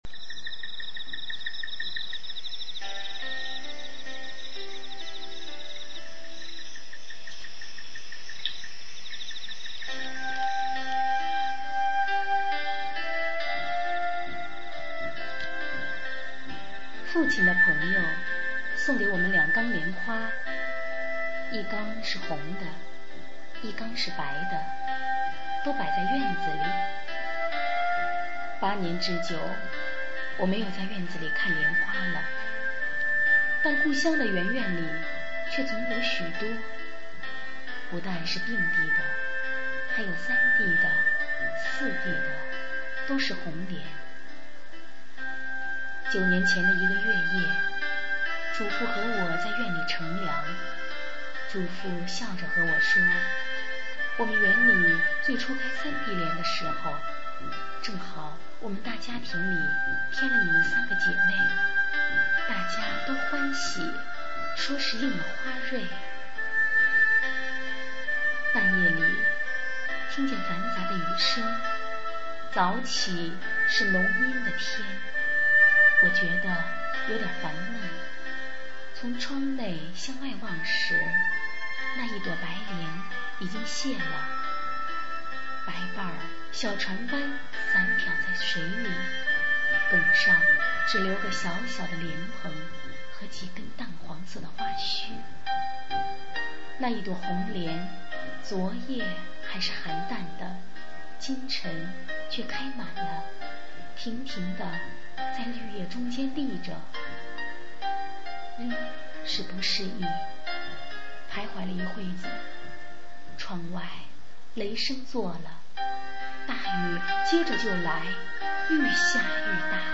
《荷叶母亲》配乐朗诵.mp3